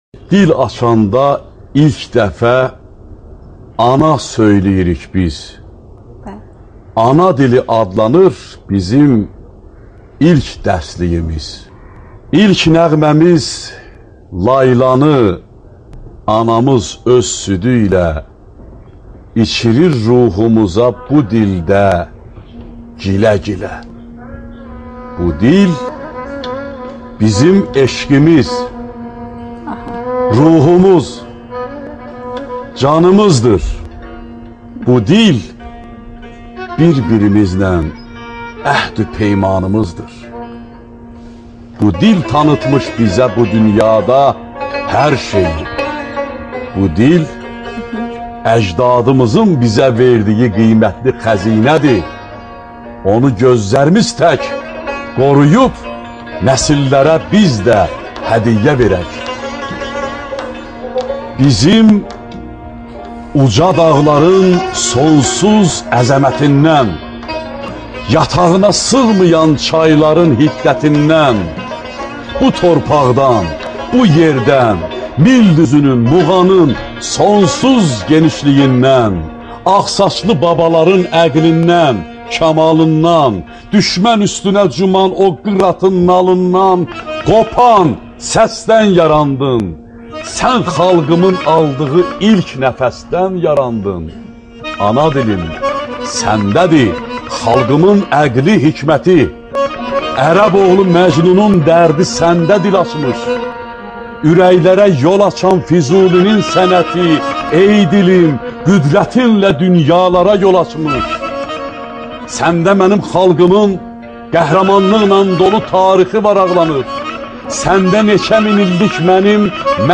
ŞEİRLƏR
AKTYORLARIN İFASINDA